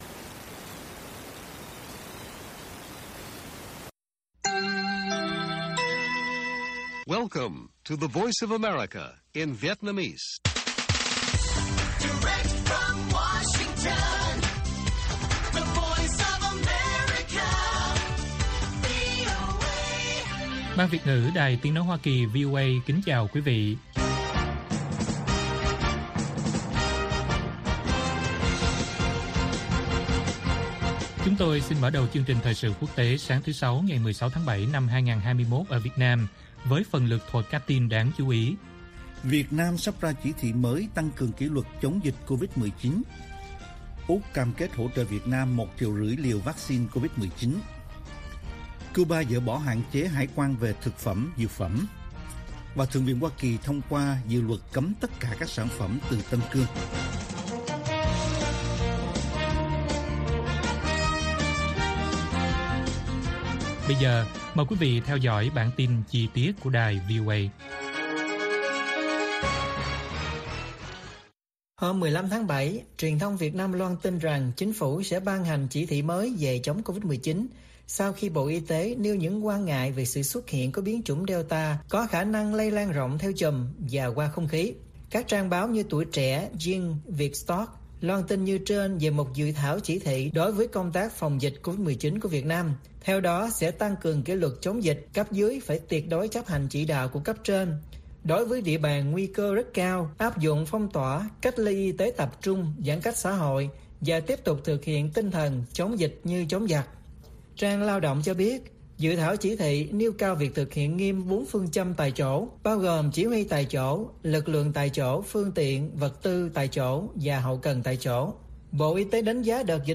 Bản tin VOA ngày 16/7/2021